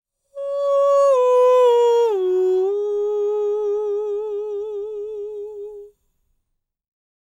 Index of /90_sSampleCDs/ILIO - Vocal Planet VOL-3 - Jazz & FX/Partition F/2 SA FALSETO